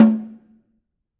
Snare2-HitNS_v6_rr2_Sum.wav